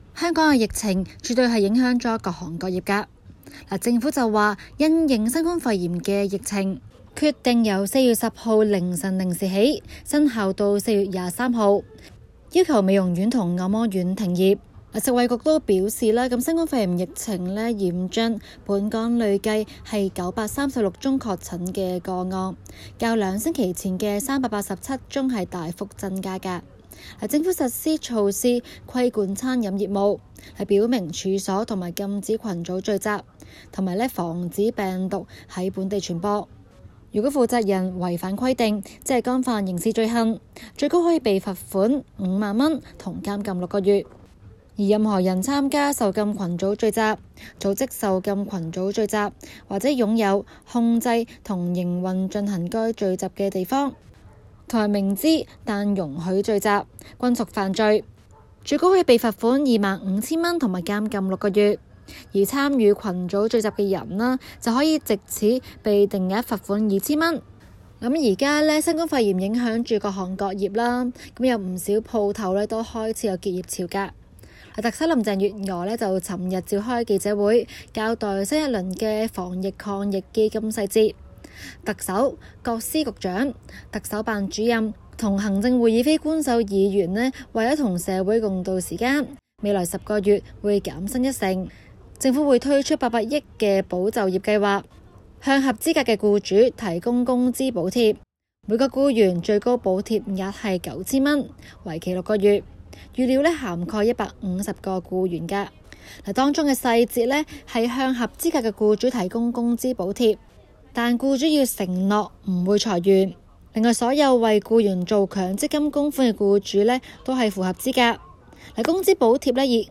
今期 【中港快訊 】環節報導與大家跟進特區政府推出第二輪防疫抗疫基金」細節，涉及金額1,300億元。